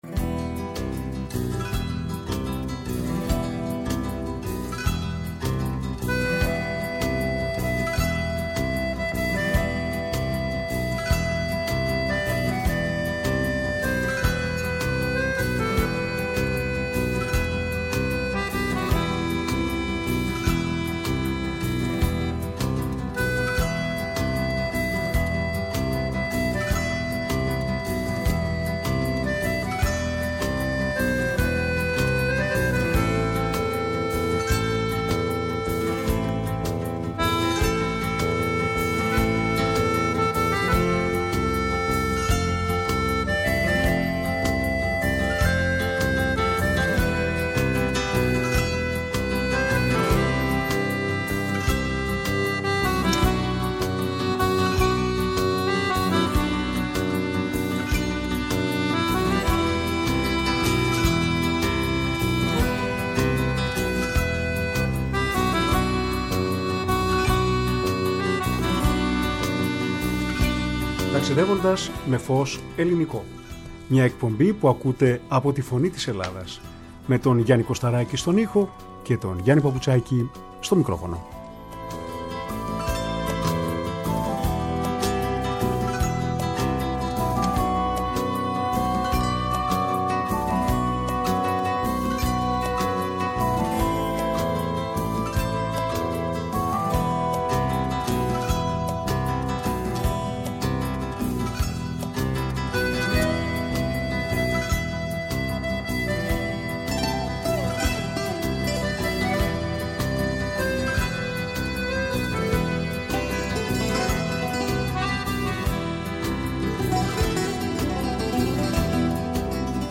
όπου έπαιξε με την κιθάρα του μπαλάντες σε ποίηση παλιών και σύγχρονων ποιητών.